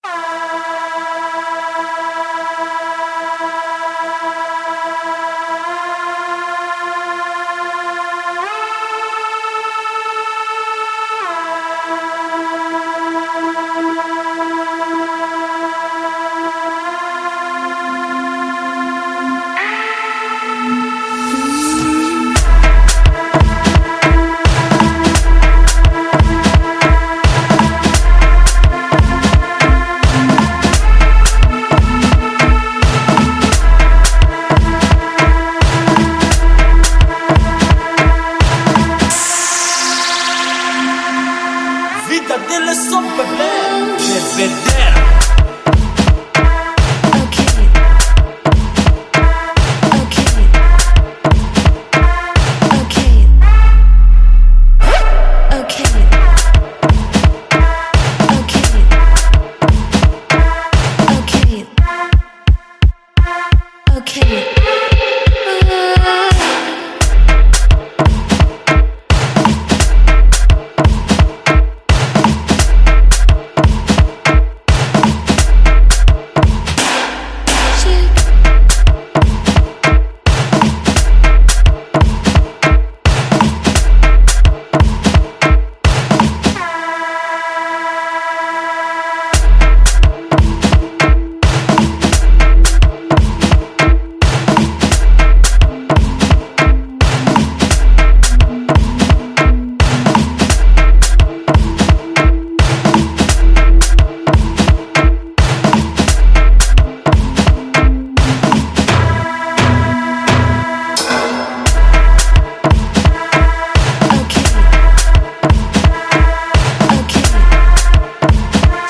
Música sincera, inadulterada, instintiva